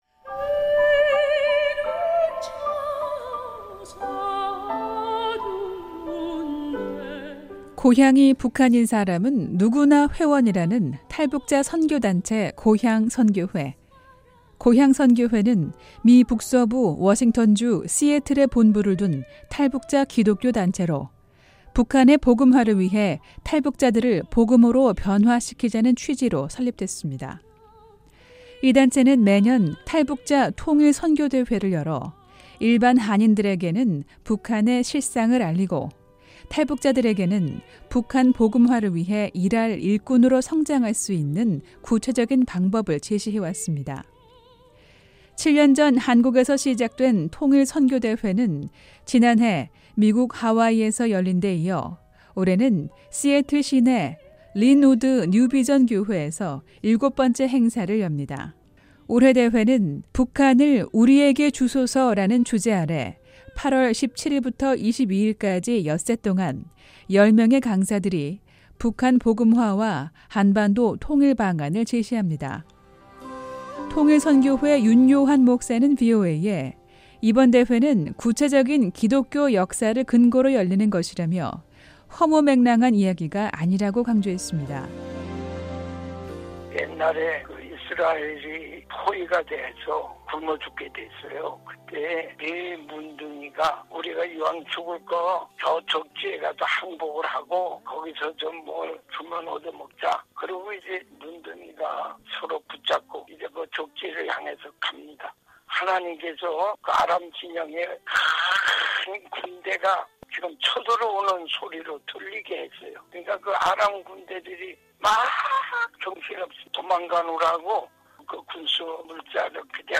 매주 화요일 화제성 소식을 전해 드리는 `뉴스 투데이 풍경'입니다. 미국 북서부 워싱턴 주 시애틀의 한인 선교단체가 다음주에 통일선교대회를 엽니다.